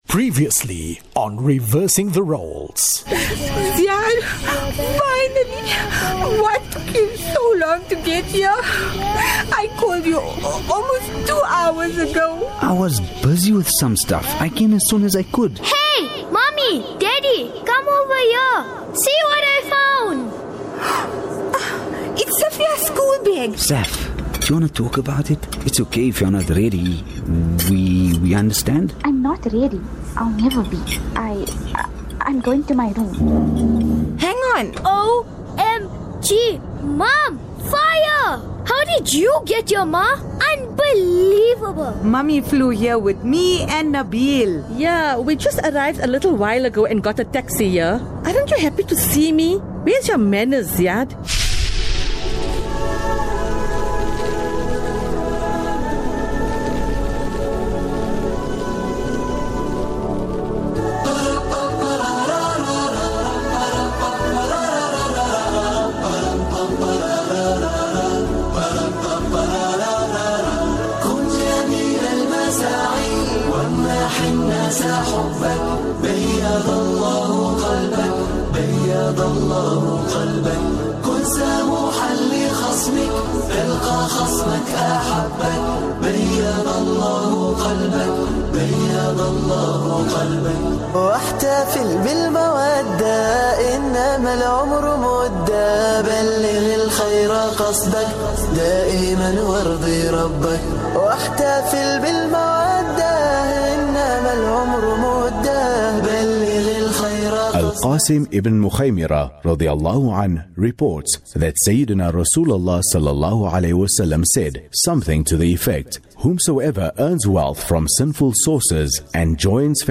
Drama 1446 – Reversing the Roles – Episode 13: All Aboard!